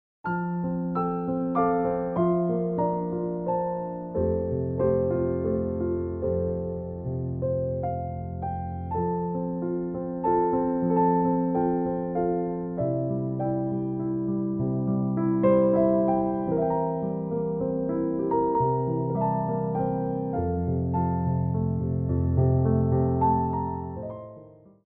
Adagio
3/4 (8x8)